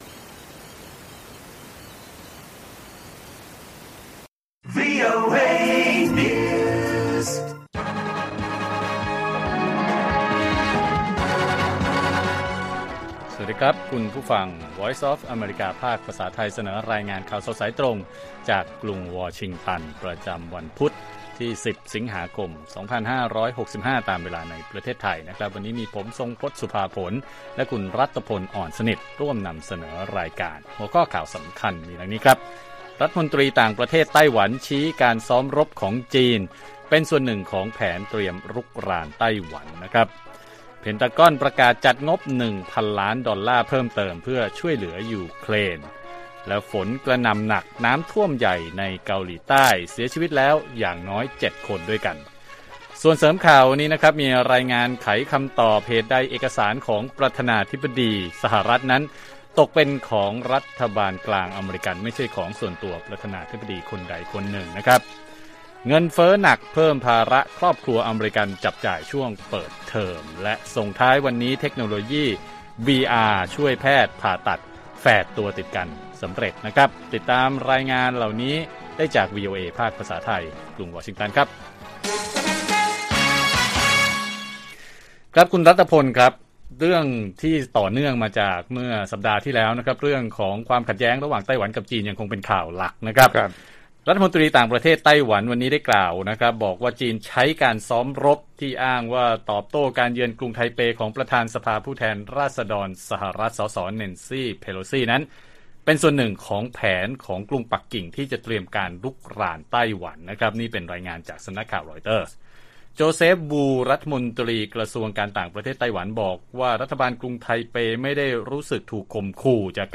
ข่าวสดสายตรงจากวีโอเอไทย 6:30 – 7:00 น. วันที่ 10 ส.ค. 65